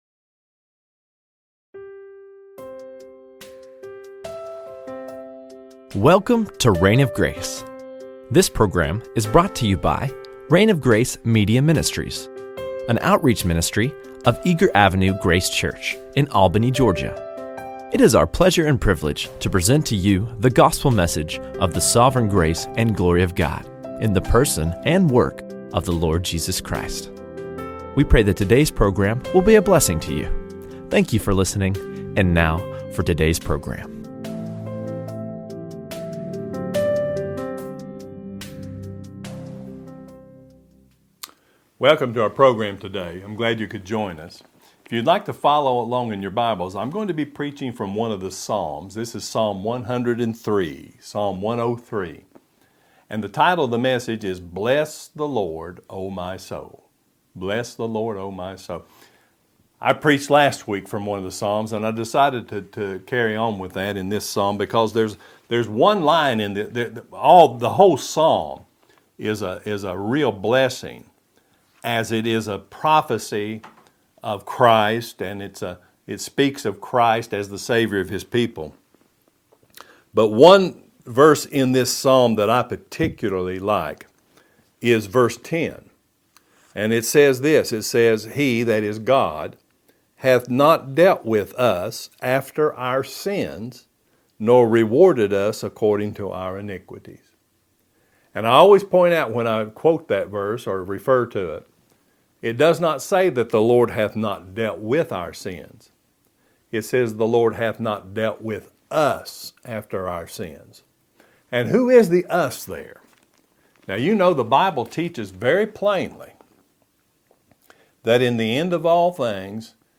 Bless the Lord, Oh my Soul | SermonAudio Broadcaster is Live View the Live Stream Share this sermon Disabled by adblocker Copy URL Copied!